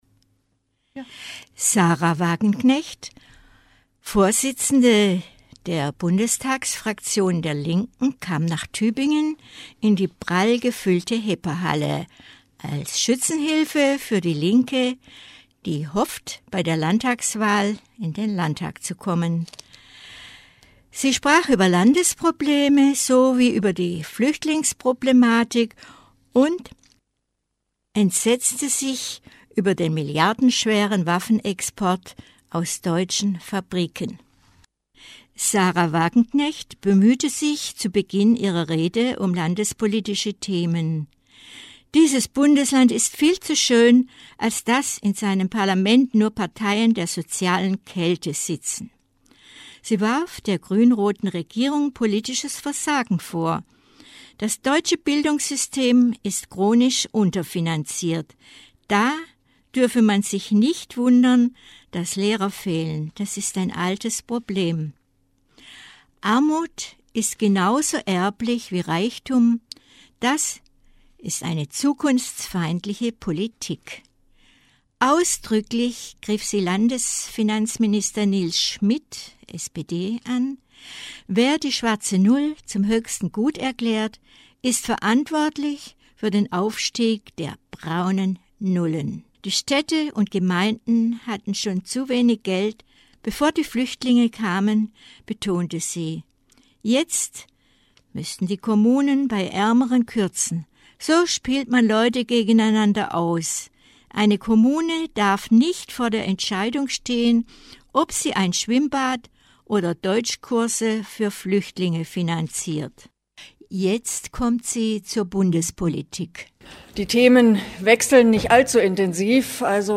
Kommentar zur AfD